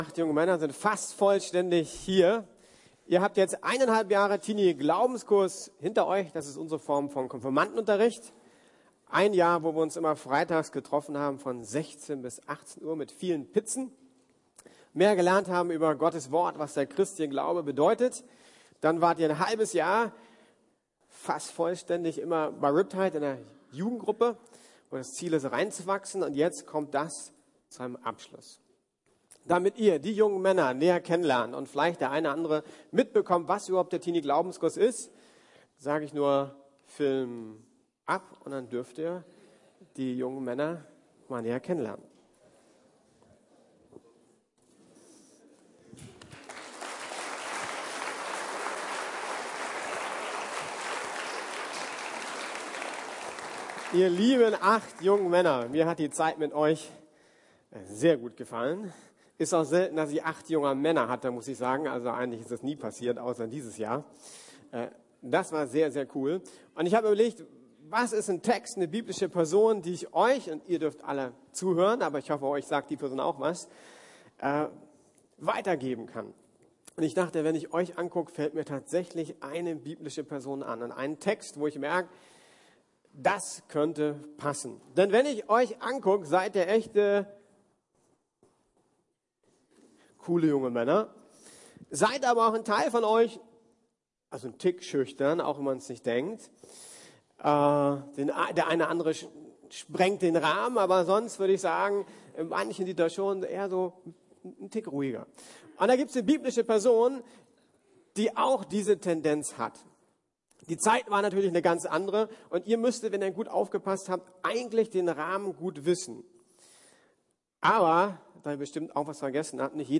Gott braucht keine Helden ~ Predigten der LUKAS GEMEINDE Podcast